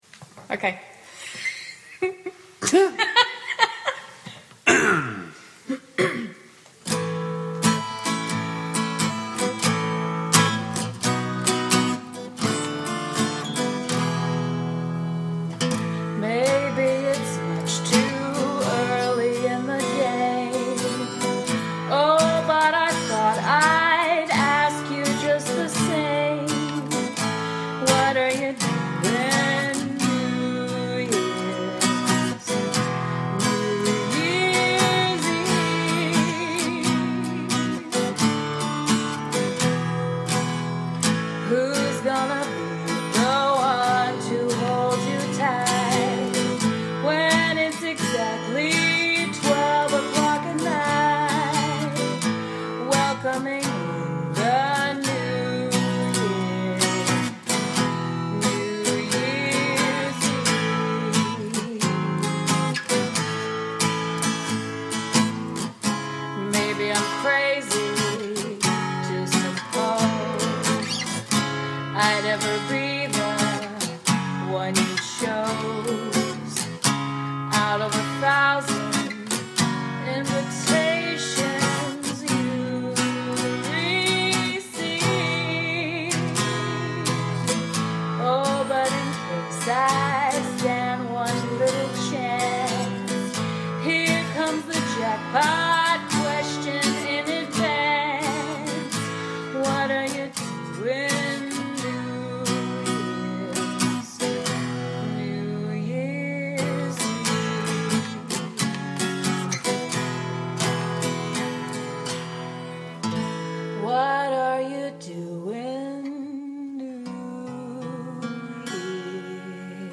guitar accompaniment